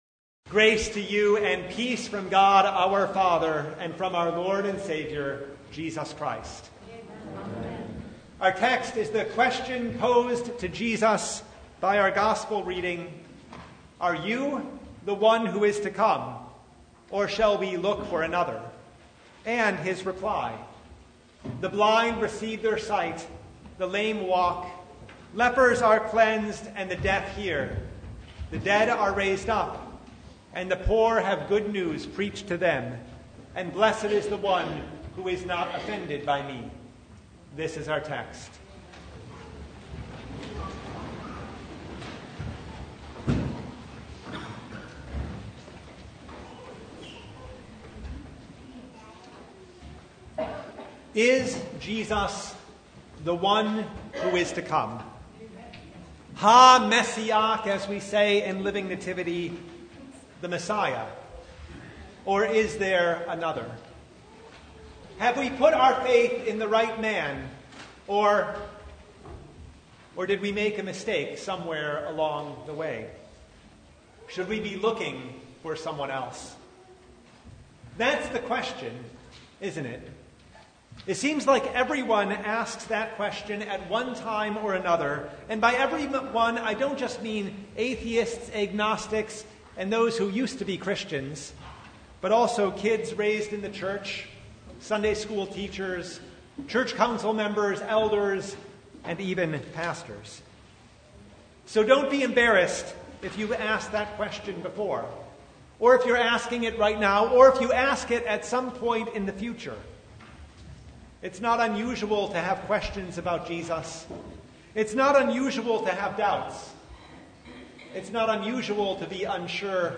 Luke 7:18-35 Service Type: Advent Is Jesus the One who is to come